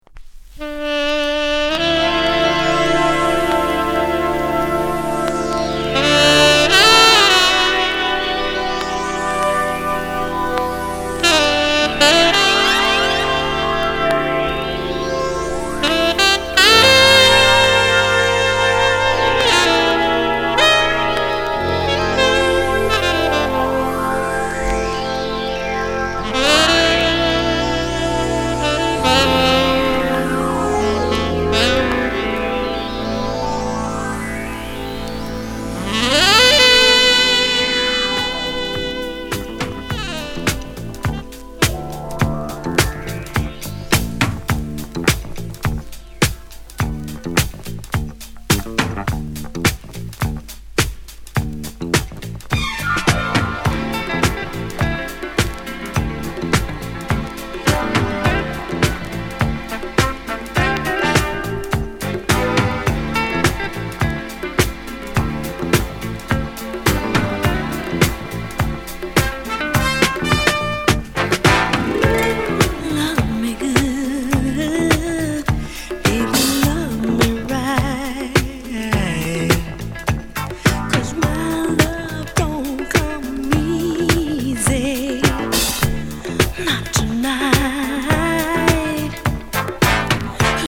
Genre: Rare Groove